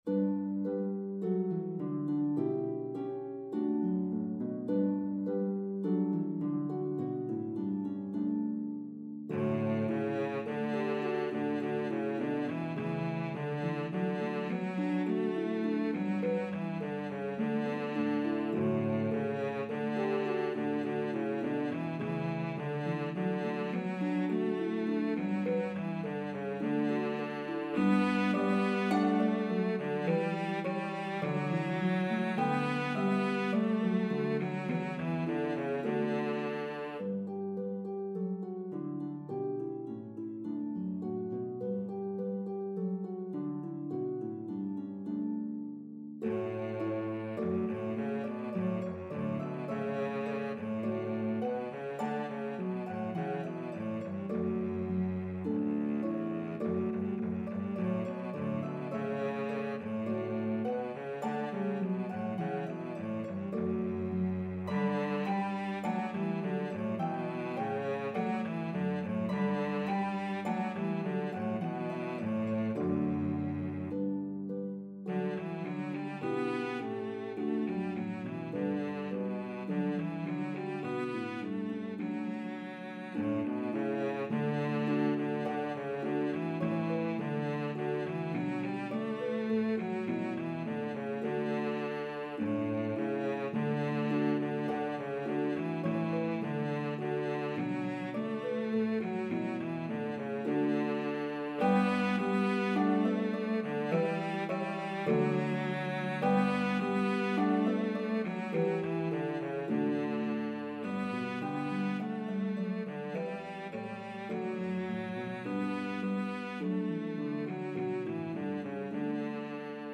hymn tune
This arrangement has 4 verses separated by interludes.